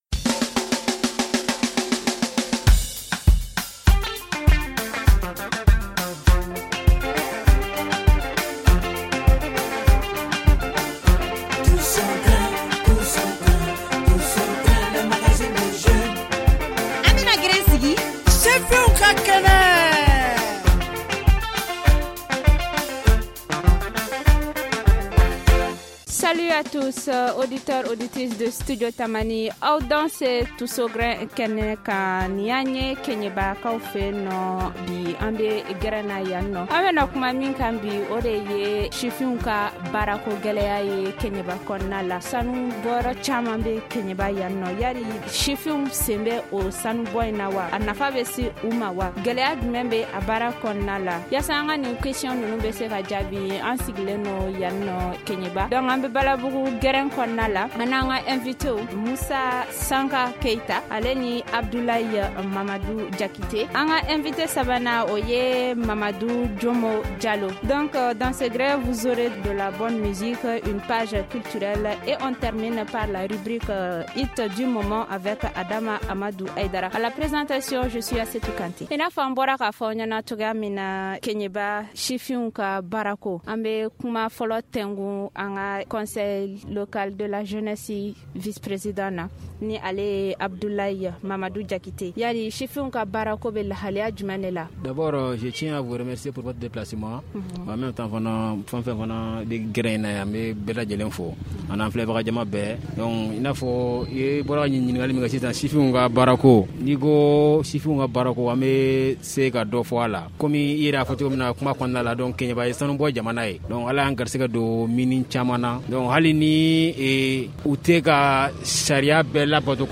L’équipe « Tous au Grin » s’est rendue cette semaine à Kéniéba dans la région de Kayes où la terre est riche en or.